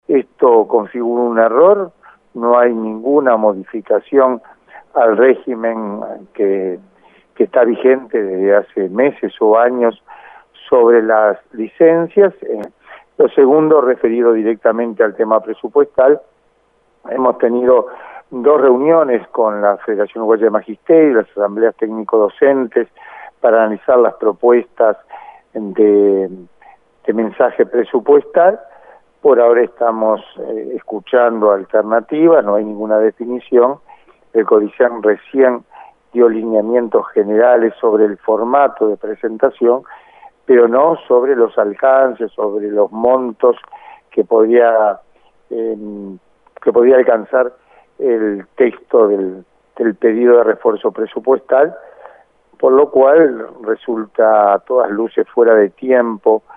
El Director Nacional de Educación Primaria, Héctor Florit dijo a El Espectador que es prematuro parar por temas presupuestales y que los reclamos que hacen los docentes, sobre licencias especiales, ya fueron contemplados.